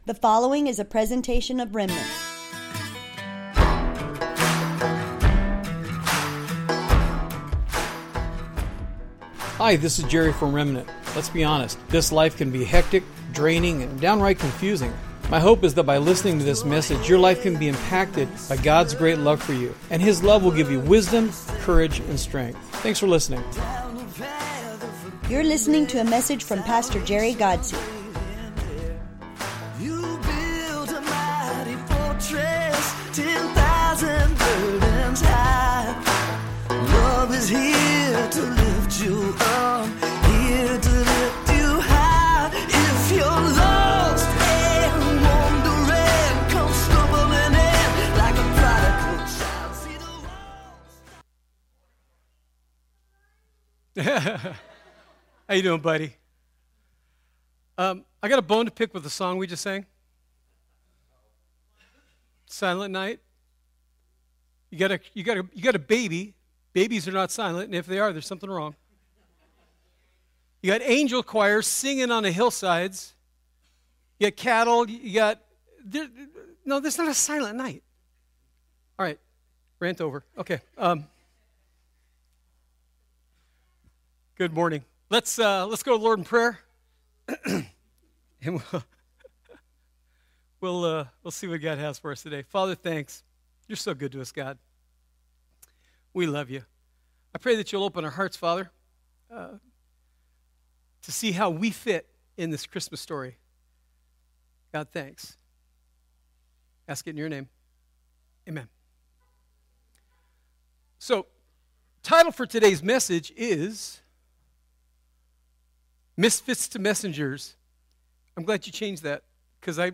Welcome to the livestream of our Christmas worship gathering at Remnant Church in Imperial Valley, CA. Today